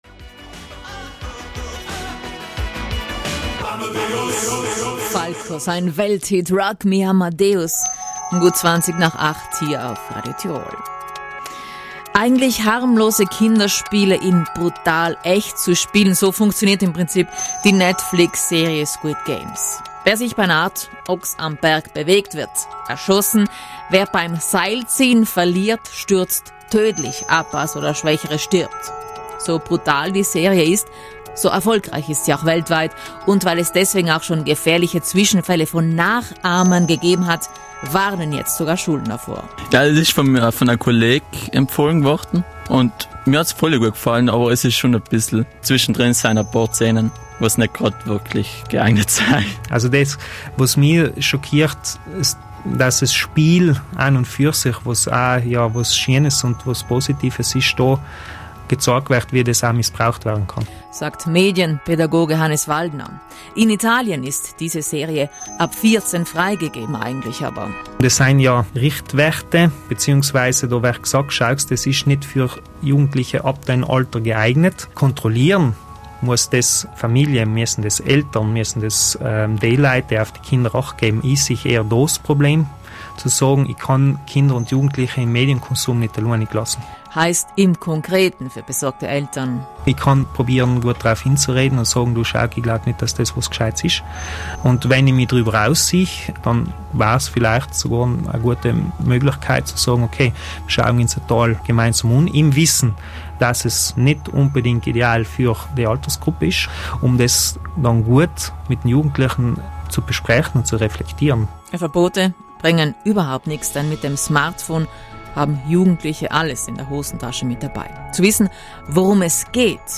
Wir haben mit ihm darüber gesprochen, wie wir als Eltern reagieren können, wenn unsere Kinder solche Serien sehen möchten.